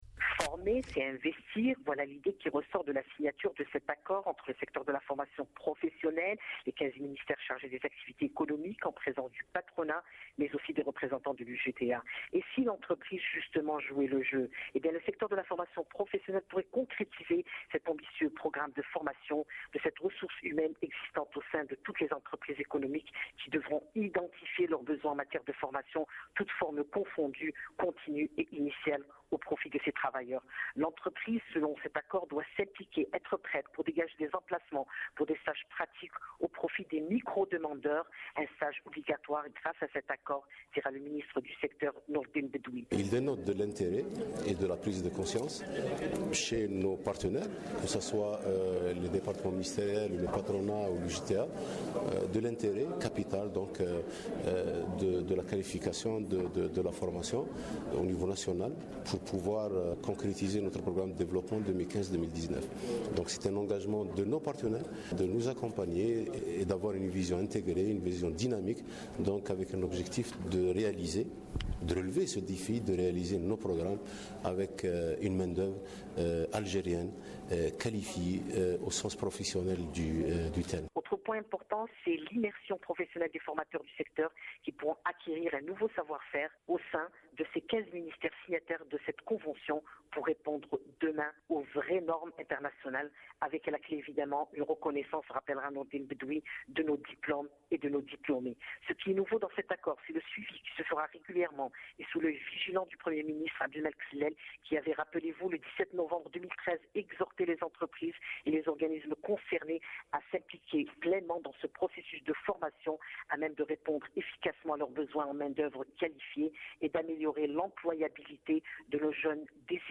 Le ministre de la Formation professionnelle expliquant l'intérêt de cette convention cadre dans ce compte rendu
Le S.G de l'UGTA s'exprimant au micro